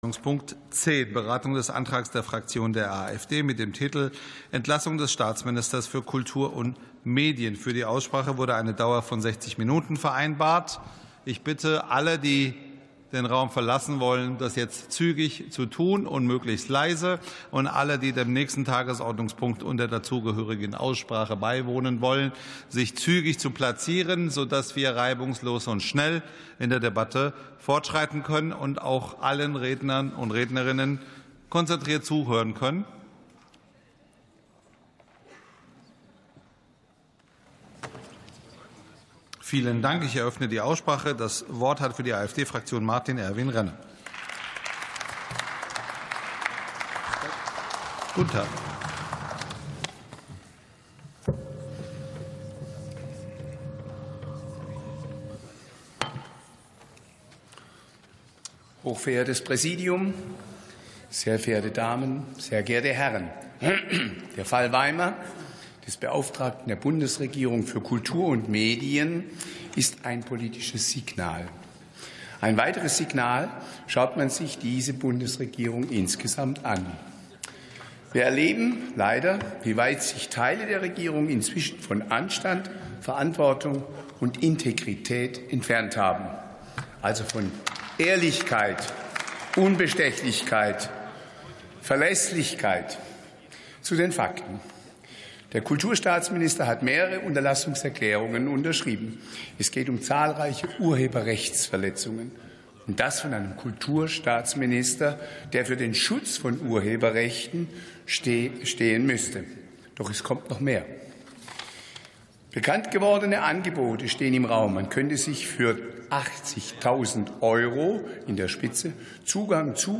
47. Sitzung vom 04.12.2025. TOP 10: Entlassungsforderung des BKM ~ Plenarsitzungen - Audio Podcasts Podcast